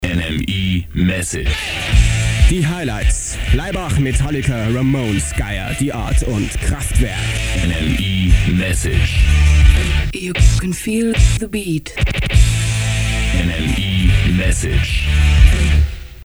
Tonbeispiel : Eine der wenigen Aufnahmen, die „überlebt“ haben, wobei nicht ganz klar ist, ob dies, so wie es hier zu hören ist, live ausgestrahlt wurde. Der Beginn der Aufnahme lässt vermuten, dass im Funkhaus (Nalepastraße) die ankommende Leitung einer Sprechstelle aufgezeichnet wurde zur weiteren Bearbeitung.